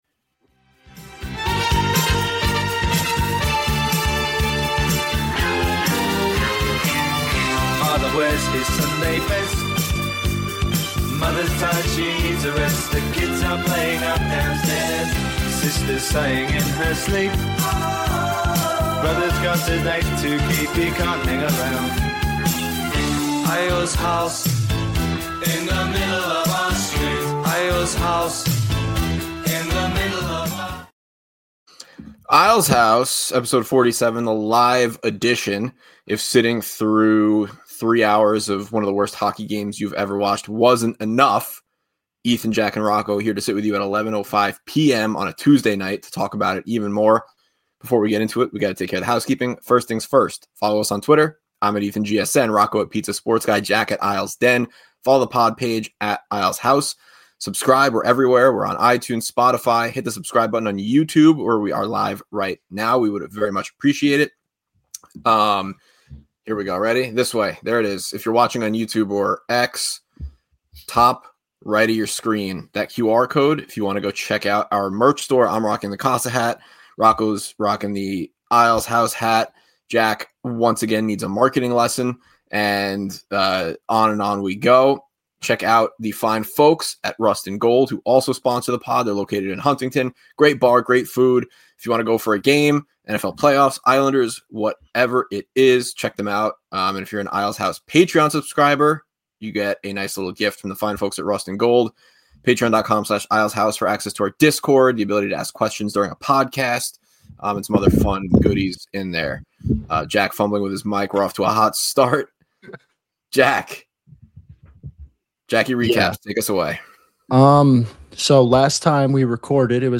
A live postgame that streamed on YouTube and our Twitter page as well.